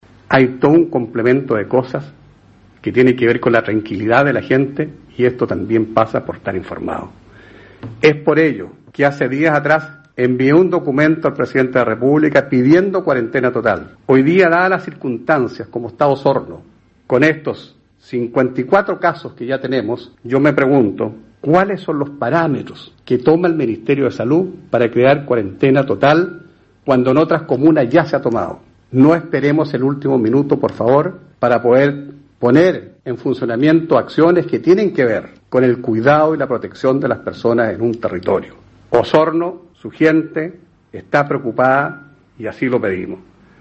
La urgente aplicación de una cuarentena para toda la comuna de Osorno debido al preocupante aumento de personas infectadas por el covid-19, solicitó nuevamente el alcalde de Osorno Jaime Bertin, durante la teleconferencia de prensa que se desarrolló desde la sala de sesiones del Edificio Consistorial.